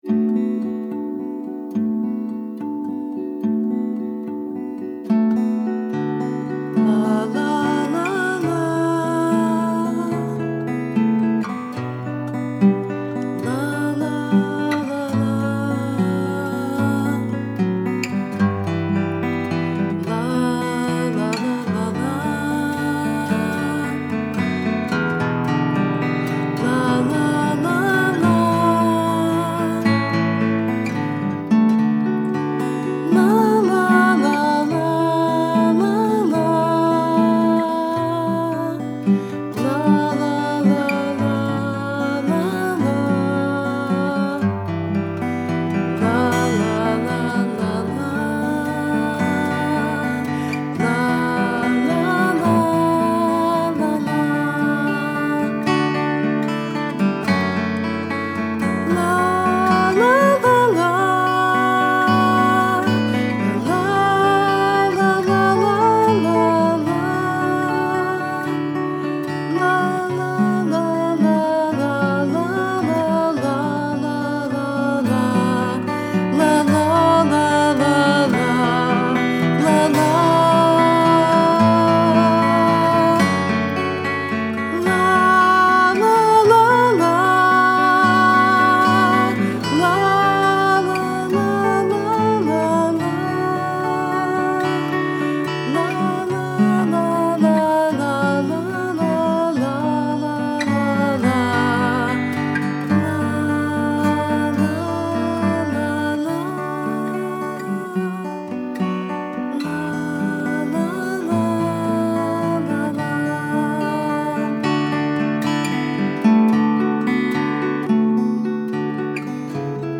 Below is a home recording that I would love to share: